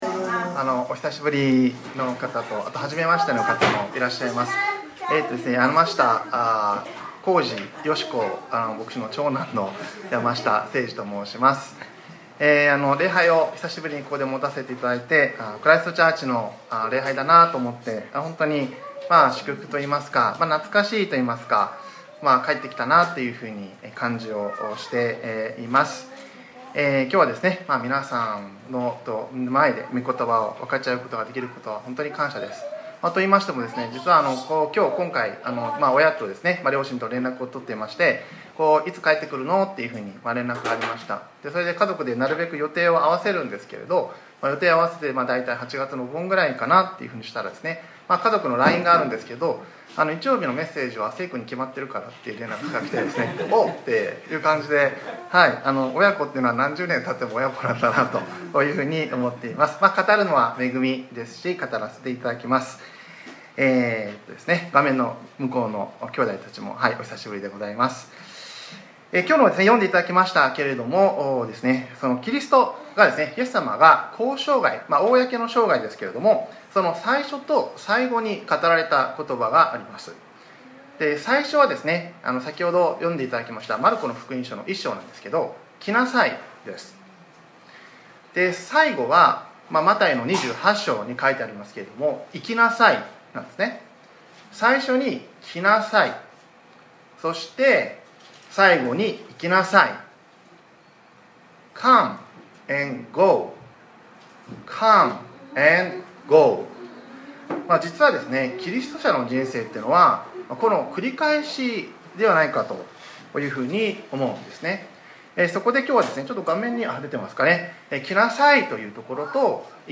2025年08月17日（日）礼拝説教『 Come ＆ Go 』 | クライストチャーチ久留米教会